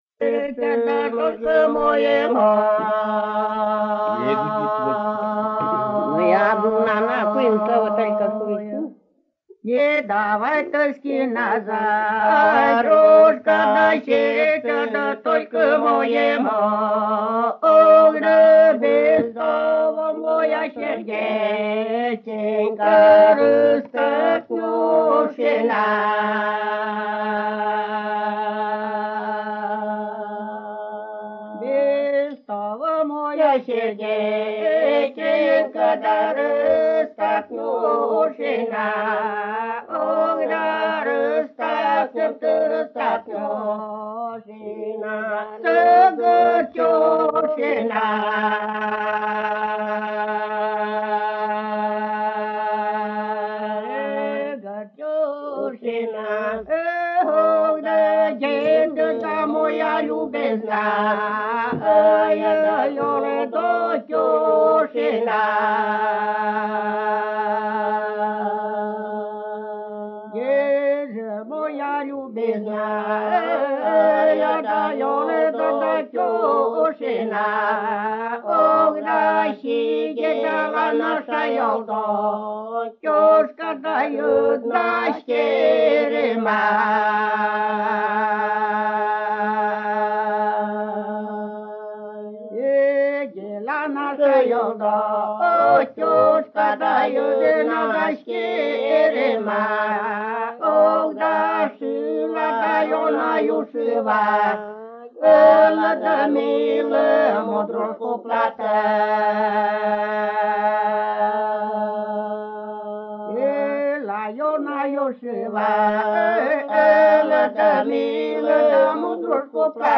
Рождественские игрища в селе Прокопьевка Прилузского района Республики Коми
01 Хороводная песня «Ловлина моя» в исполнении жителей с. Прокопьевка Прилузского р-на Республики Коми